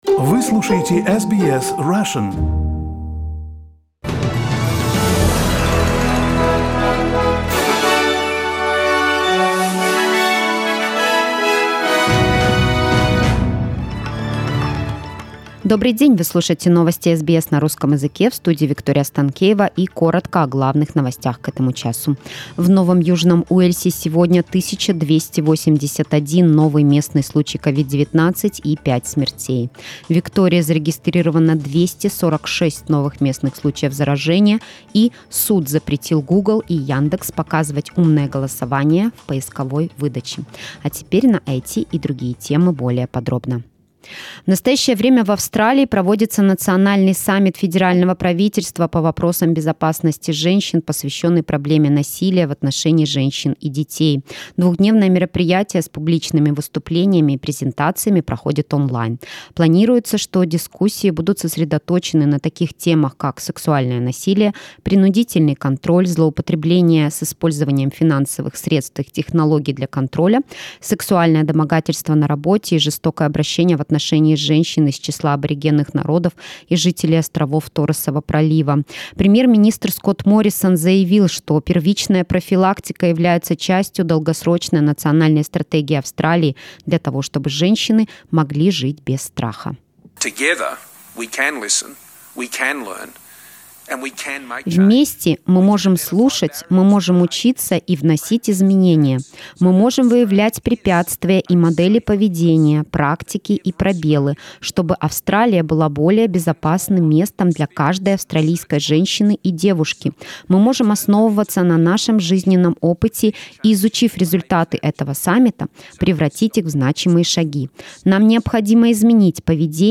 SBS news in Russian - 6.09